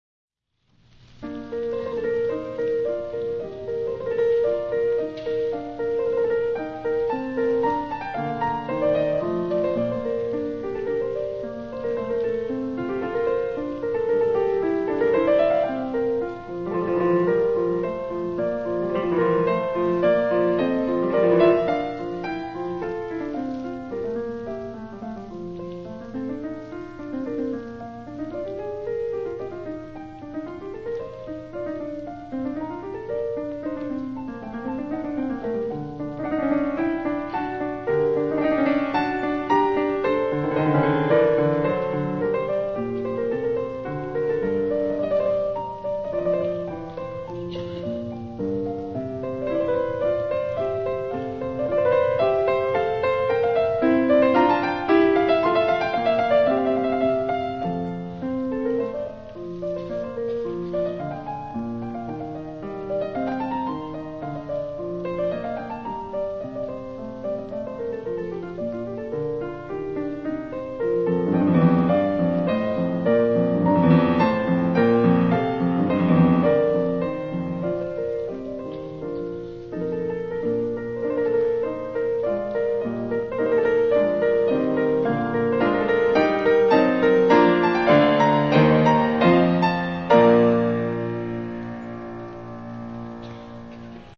スケールの大きさこそあり ませんが、表現意欲にあふれたユニークな演奏を聴くことができます。時折、アルゲリッチ的な激しさも見せつつ、内省的な解釈もきかせています。
ですが、リズム感の良さと、素直な音楽性は、そういった欠点を帳 消しにするものを提供してくれると思います。
以下に、カーネギーホールで弾かれたパルティータの前奏曲をアップロードしておきます。（